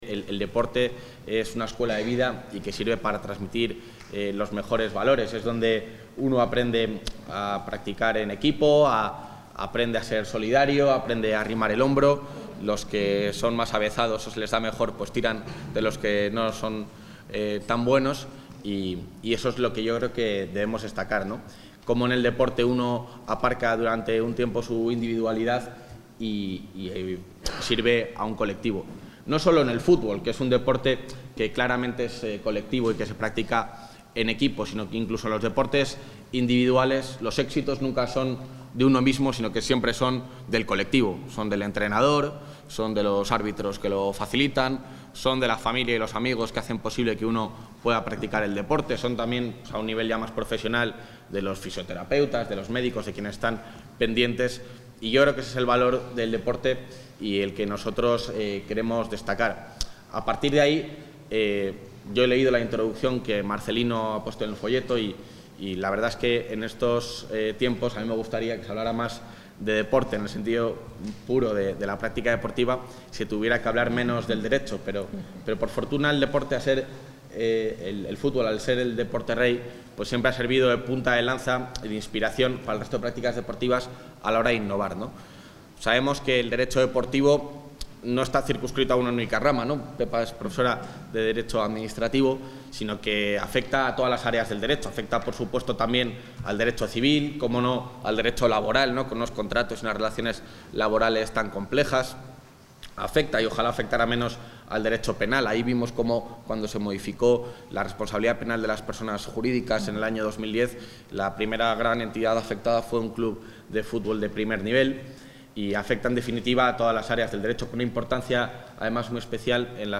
Intervención del vicepresidente.
El vicepresidente de la Junta de Castilla y León ha inaugurado en Salamanca el Congreso Internacional ‘Derecho del Fútbol’, que concluye mañana.